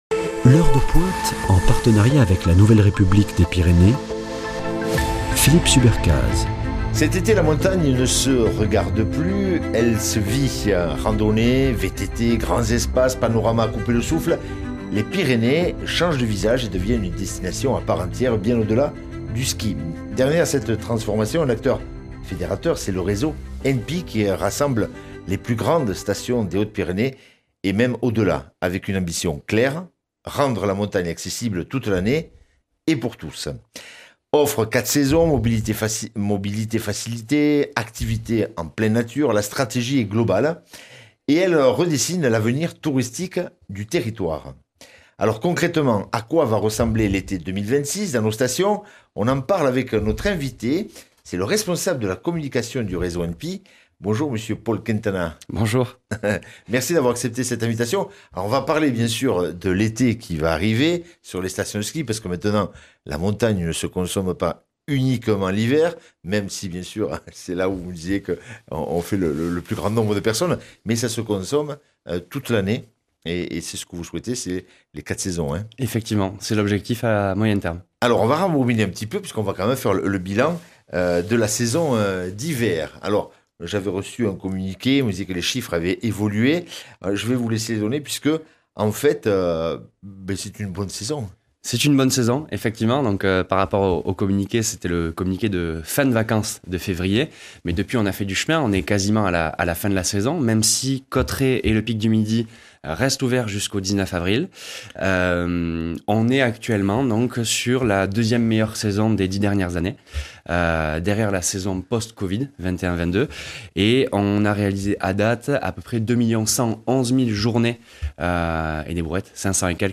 Invité de L'Heure de Pointe